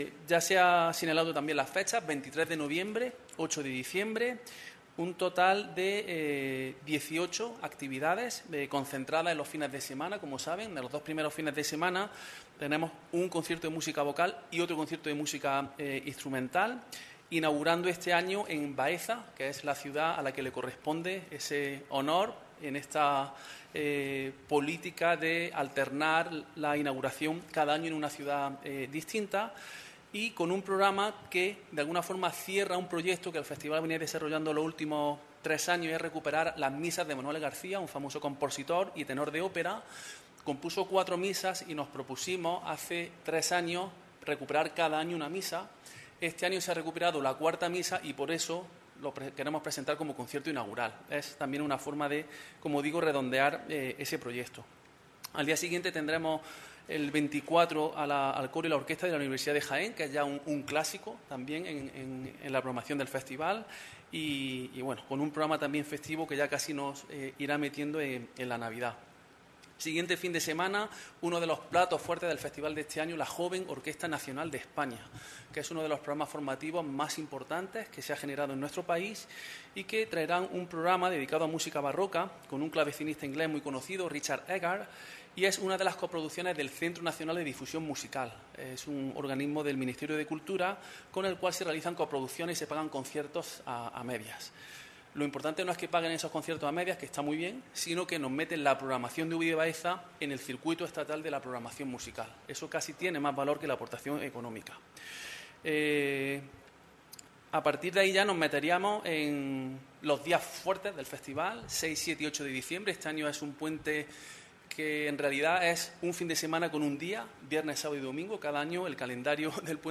Audio de las declaraciones